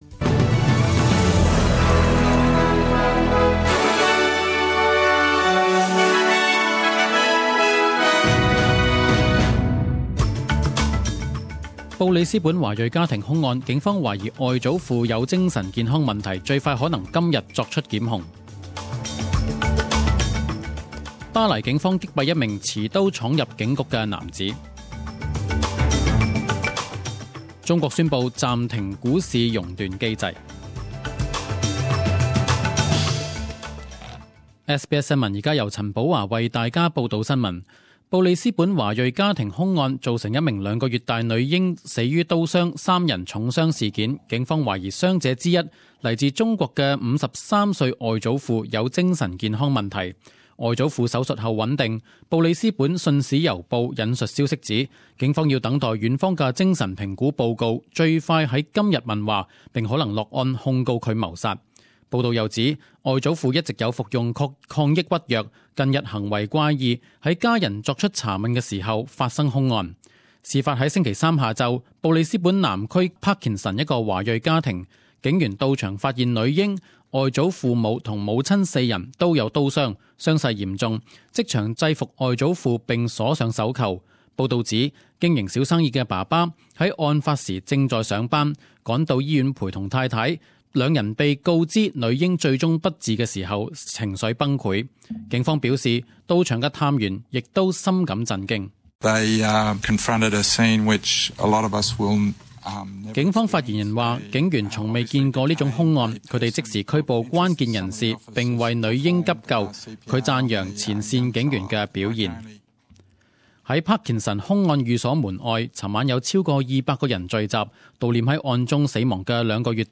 十点钟新闻报导 （一月八日）
10am News Bulletin 08.01.2016 Source: SBS